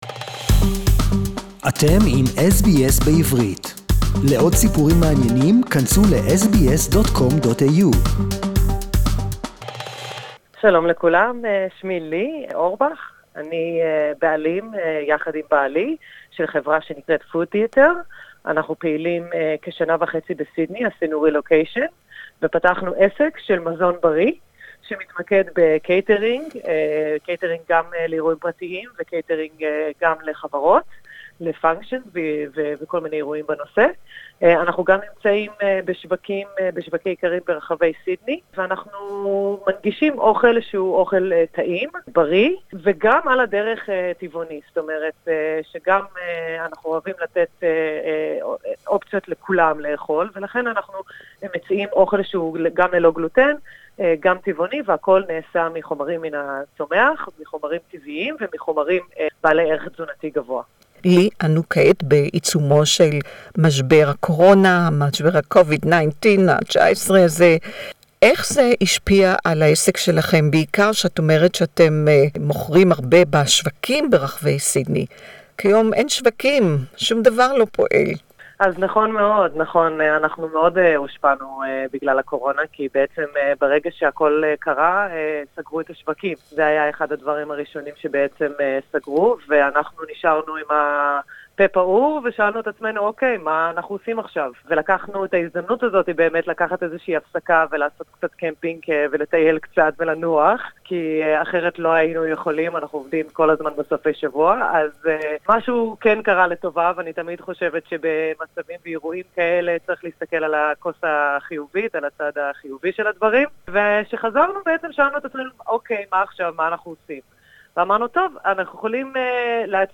"Food Theatre" is surviving the Coronavirus crisis" (Hebrew interview)